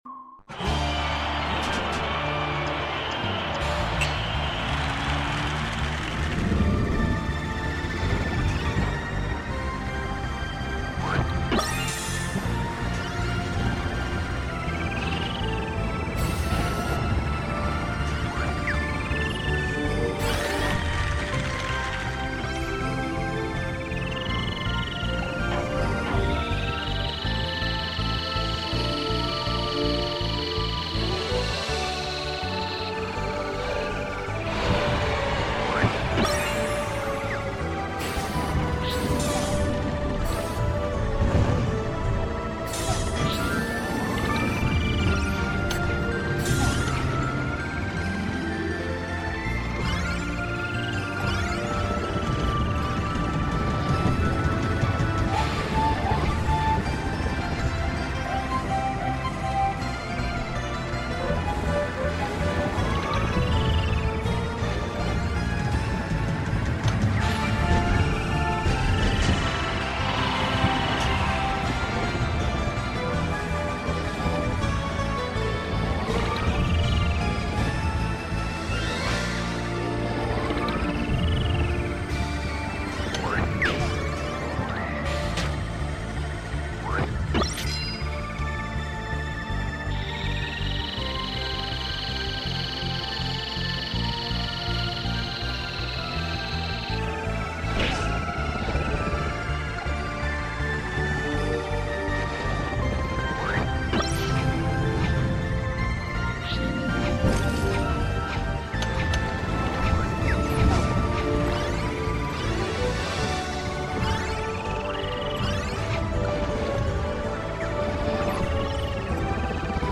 Gameplay